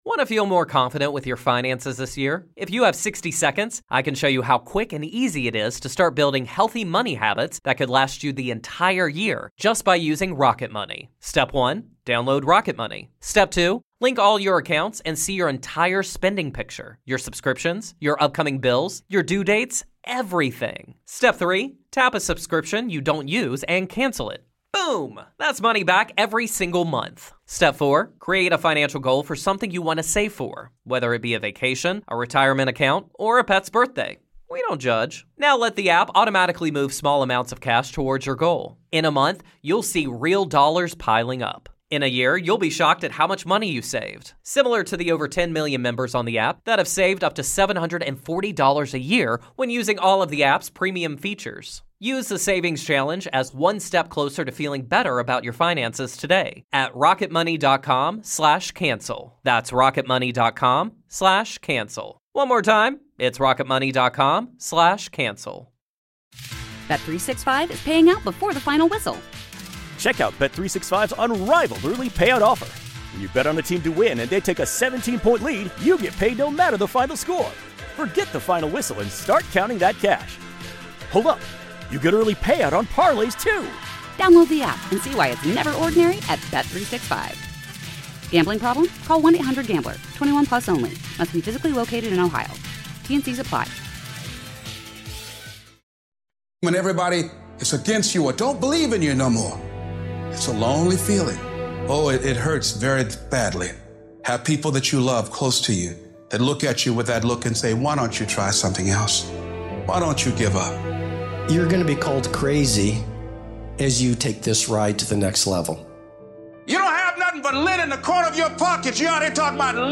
Spoken by: Les Brown